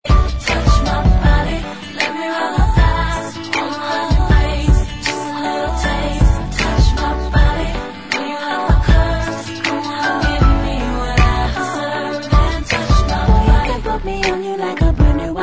• Dance Ringtones